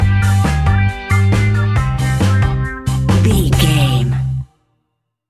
Classic reggae music with that skank bounce reggae feeling.
Aeolian/Minor
laid back
off beat
drums
skank guitar
hammond organ
percussion
horns